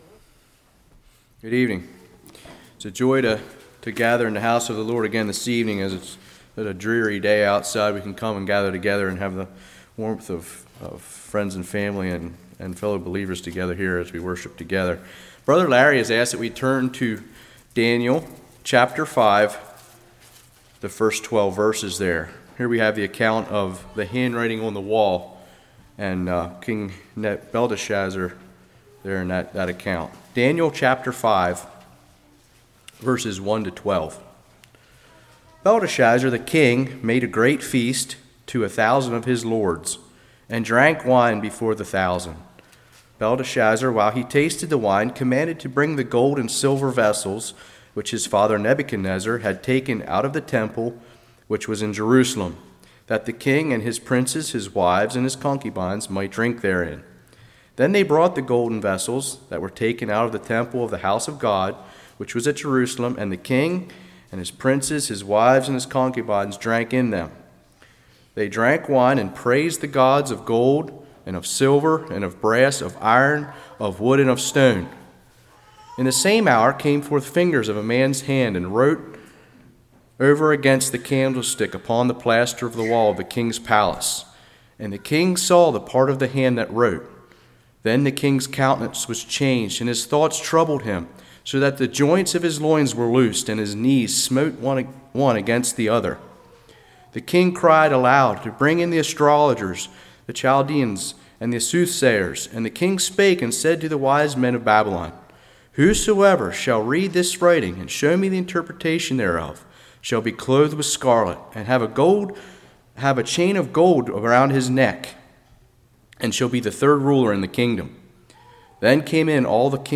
Daniel 5:1-12 Service Type: Evening Daniel Purposed In His Heart.